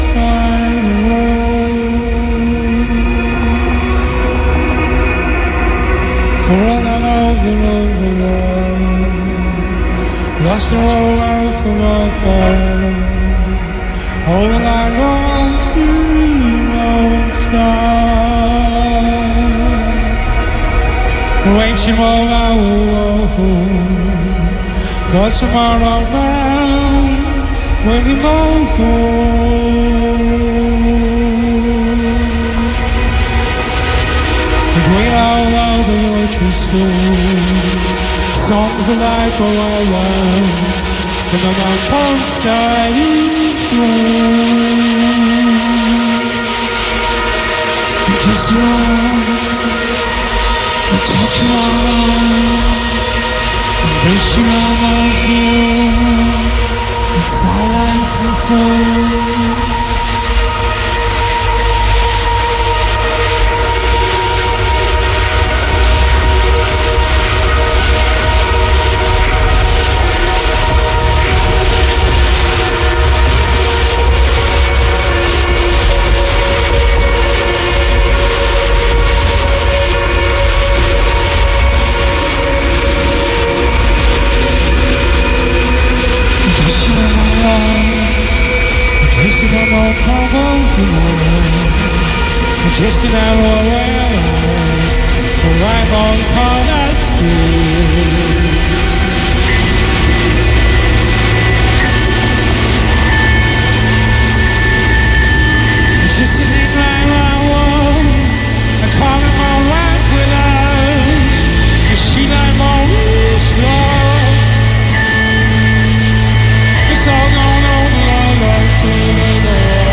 Industrial;Ambient;Noise(10577) | Radio Onda Rossa